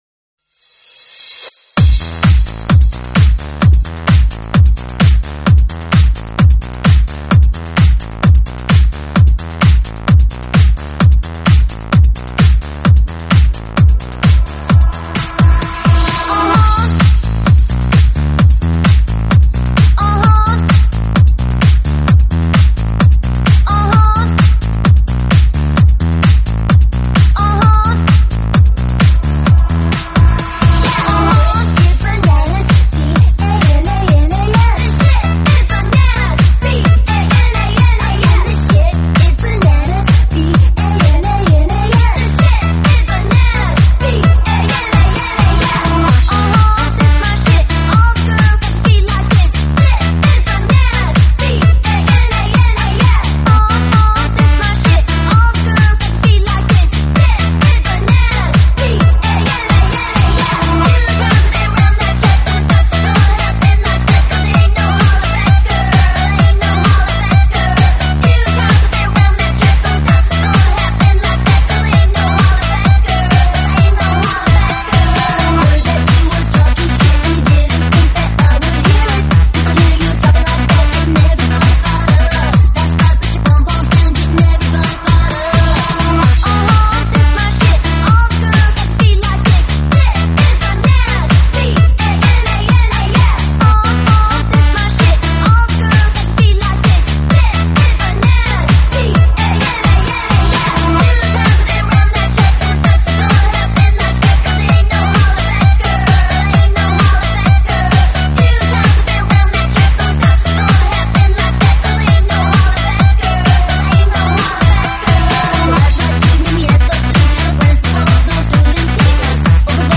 舞曲类别：新年喜庆